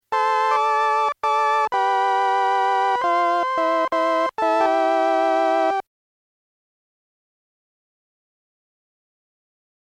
Tous ces sons ont été enregistrées directement en sortie du DX7, donc sans aucun effet : ni reverb, ni chorus.
Bontempi p.159 : Découlant des deux sons ci-dessus, voici un son excessivement vintage d’un orgue électronique à « diviseurs » des années 70.